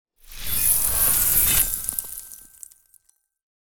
Free Frost Mage - SFX
ice_blade_07.wav